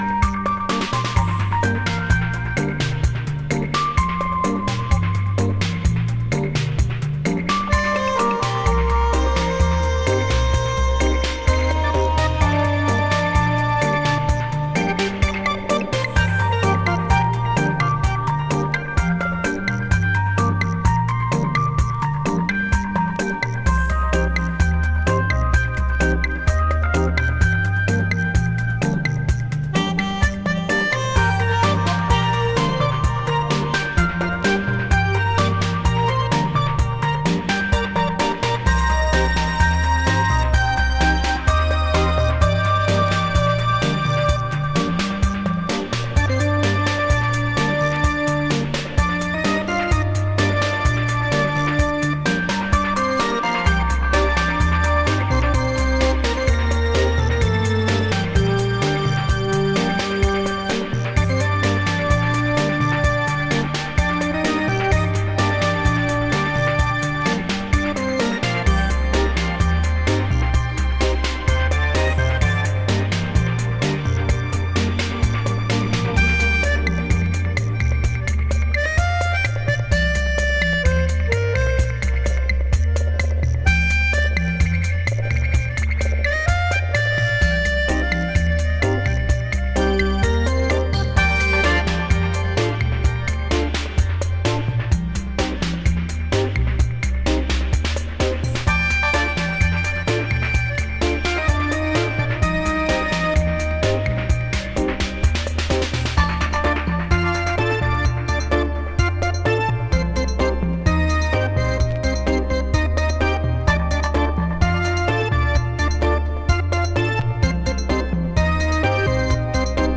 懐かしいリズムボックスのチャカポコビートの可愛らしさに惹かれる
どちらもオーセンティックな佇まいがナイス、じっくりハマる新古典派ダブ傑作です。